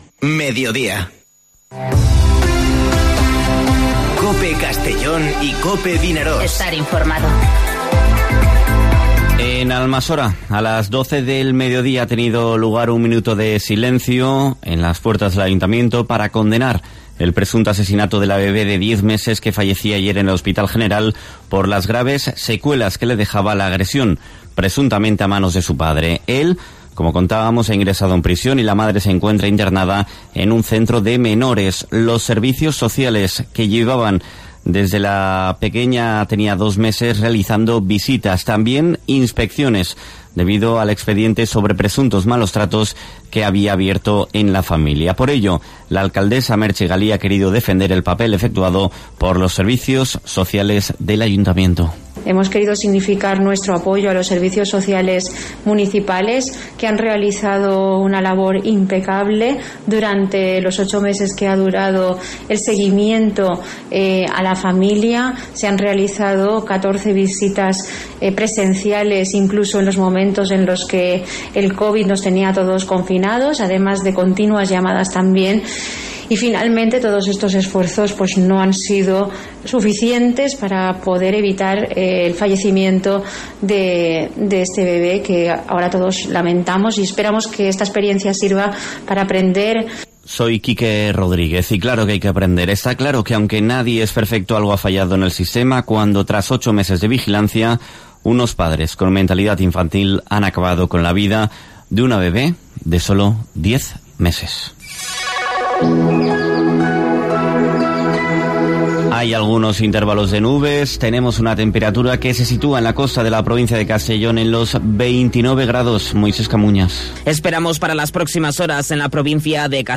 Informativo Mediodía COPE en la provincia de Castellón (16/09/2020)